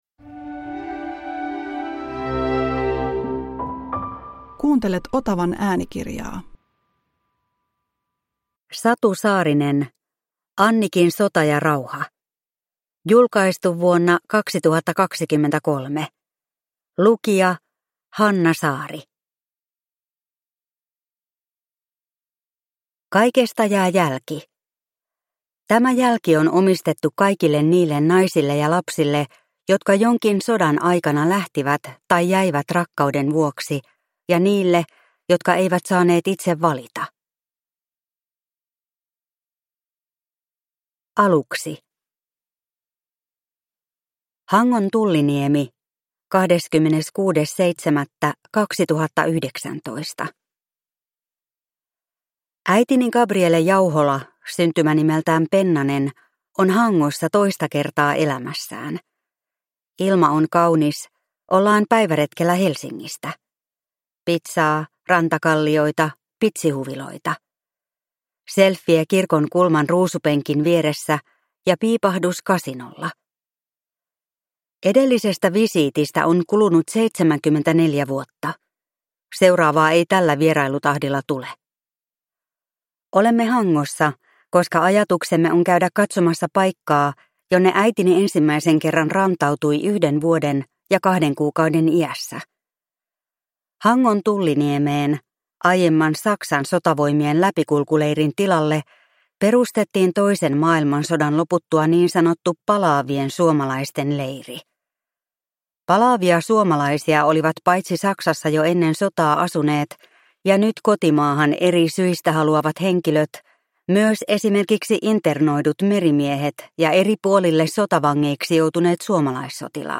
Annikin sota ja rauha – Ljudbok – Laddas ner